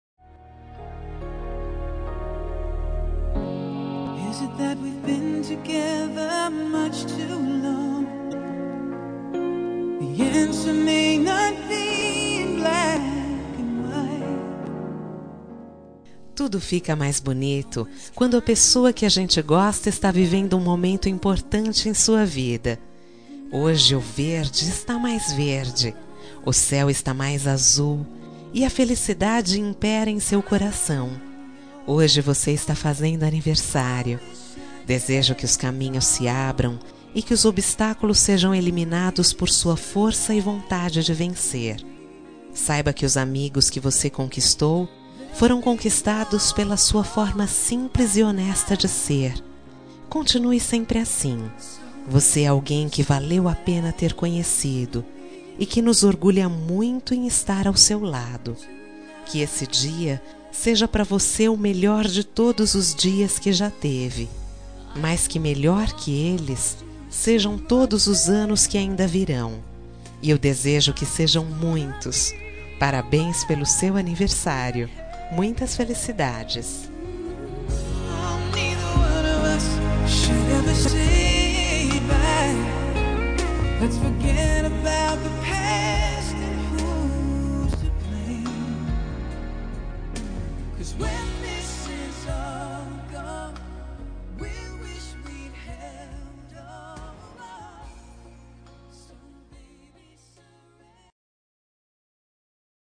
Telemensagem Aniversário de Paquera -Voz Feminina – Cód: 1233 Linda